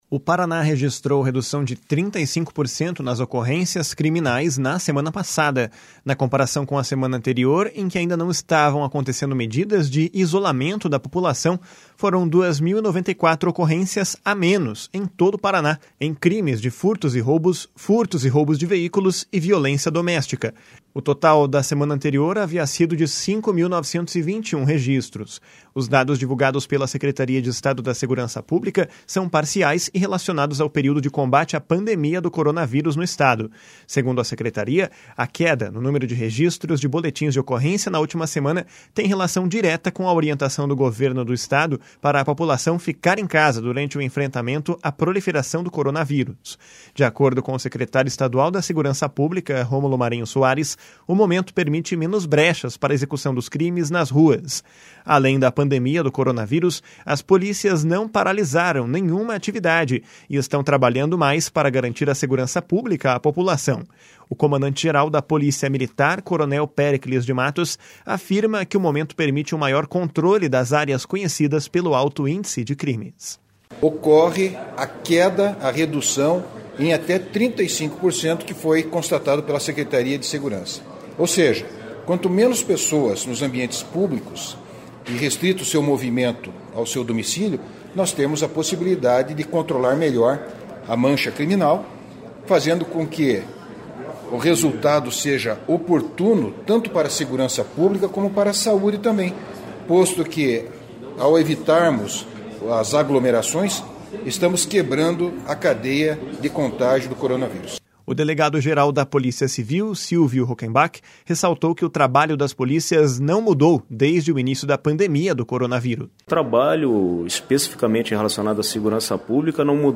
O comandante-geral da Polícia Militar, coronel Péricles de Matos, afirma que o momento permite um maior controle das áreas conhecidas pelo alto índice de crimes.
O delegado-geral da Polícia Civil, Silvio Rockembach, ressaltou que o trabalho das polícias não mudou desde o início da pandemia do coronavírus.